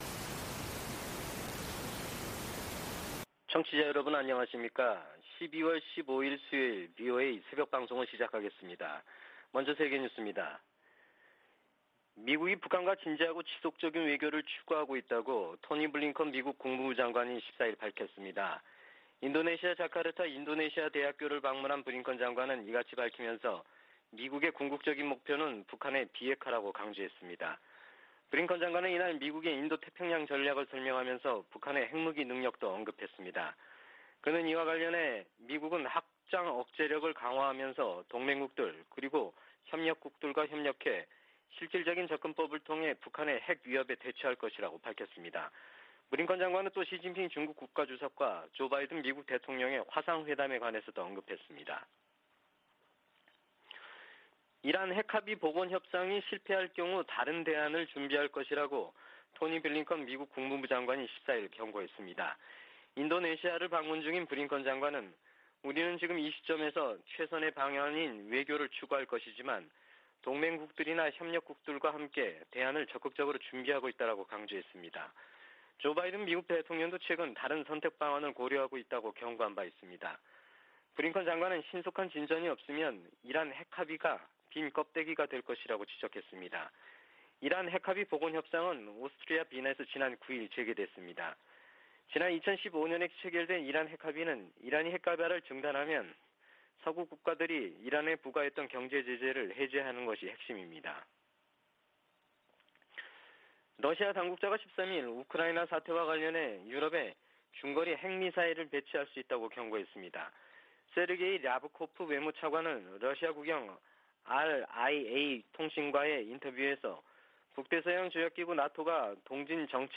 VOA 한국어 '출발 뉴스 쇼', 2021년 12월 15일 방송입니다. 미 국무부는 베이징 동계올림픽 외교적 보이콧에 관해 ‘한국 스스로 결정할 일’이라고 밝혔습니다. 미 국방부는 한국군 전시작전통제권 전환을 위한 완전운용능력(FOC) 평가를 내년 여름에 실시하는 계획을 재확인했습니다. 미 재무부가 북한 내 인권 유린에 연루된 개인과 기관을 제재한 효과가 제한적일 것이라고 전문가들은 평가했습니다.